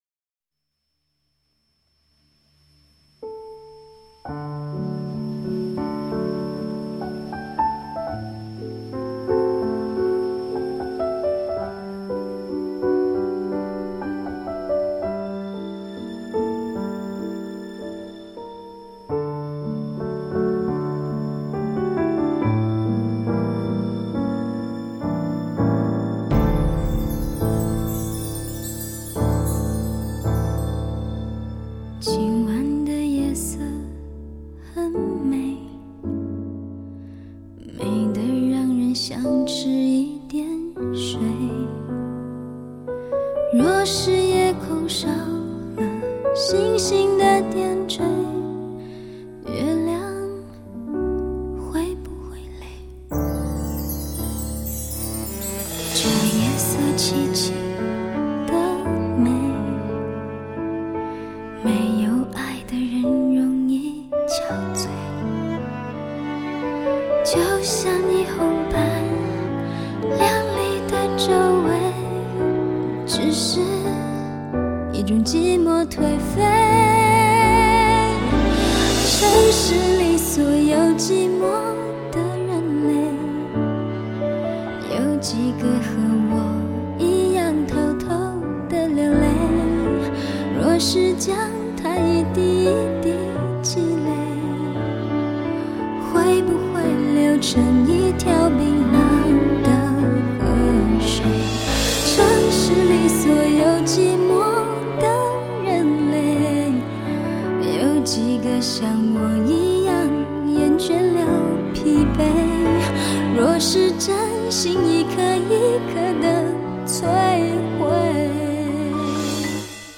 它没有很多的乐器合声，但确是一张有如清流的心灵声音。